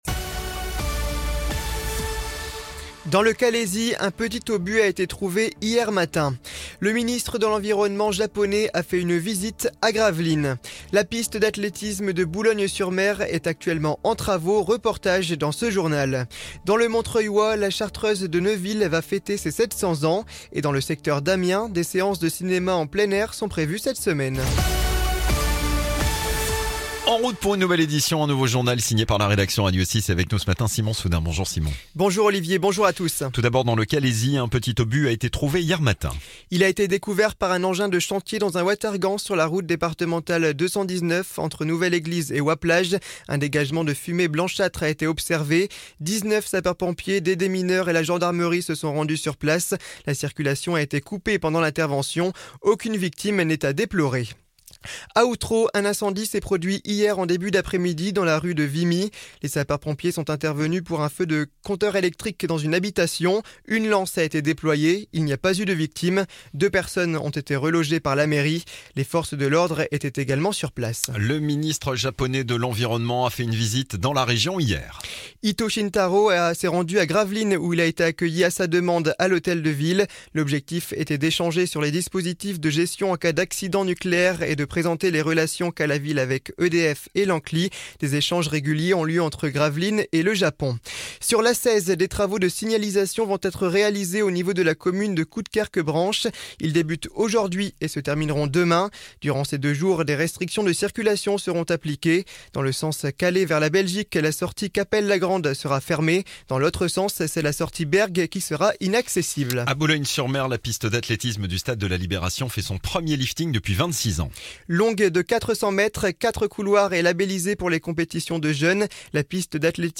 Le journal du mardi 20 août 2024
(journal de 9h)